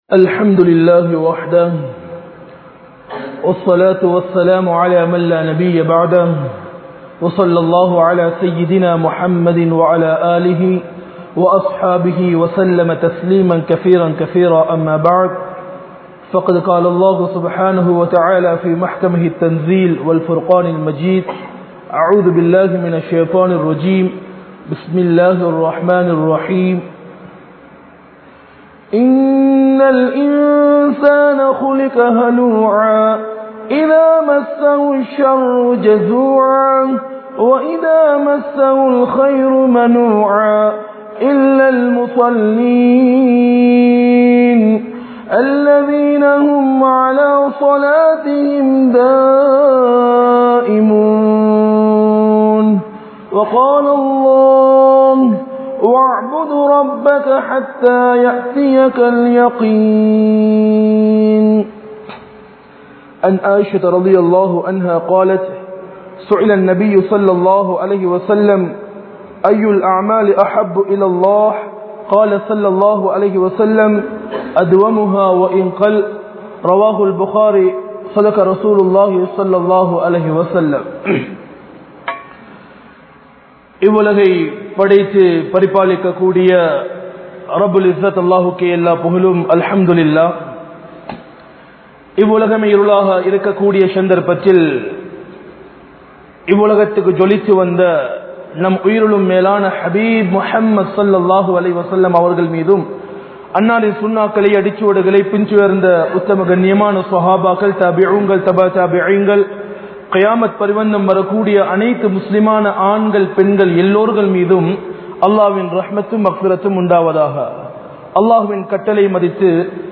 Amalhalukku Vidumurai Koduththavarhal (அமல்களுக்கு விடுமுறை கொடுத்தவர்கள்) | Audio Bayans | All Ceylon Muslim Youth Community | Addalaichenai
Kandy, Welamboda, Hidaya Jumua Masjidh